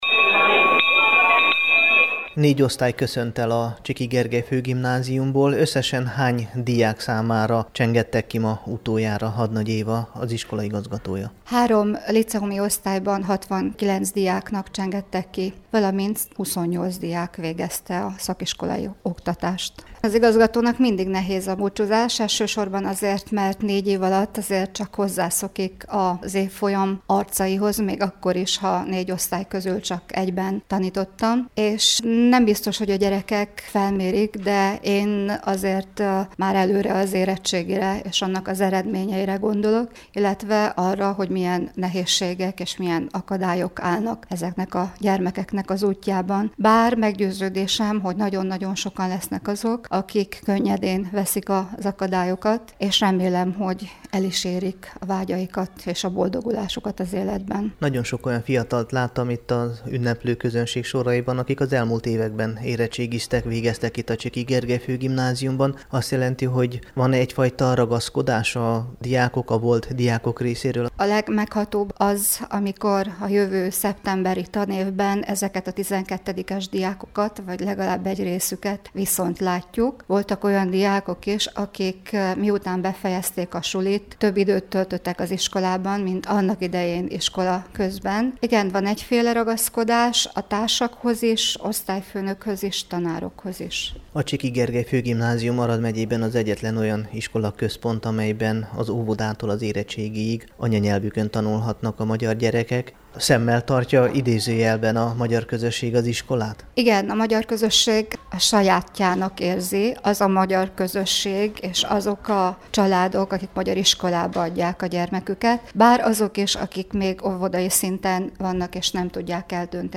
ballagas_a_csikyben_2015.mp3